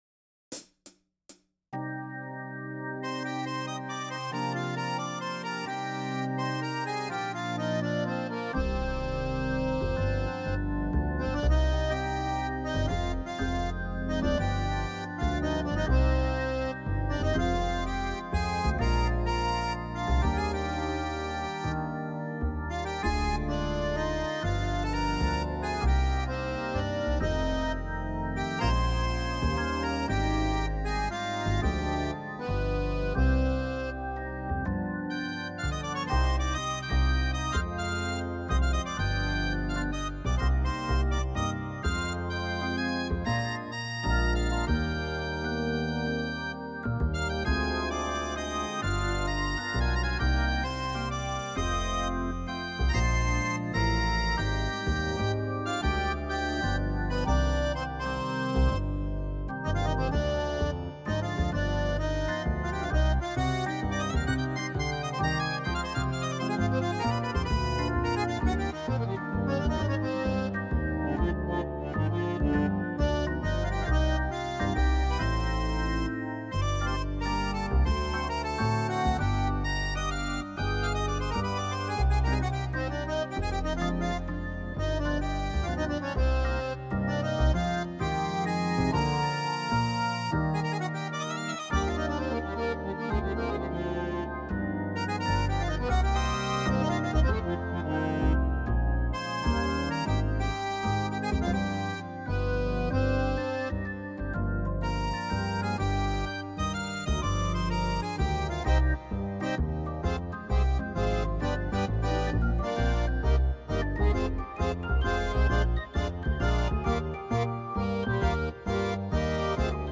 versão instrumental multipista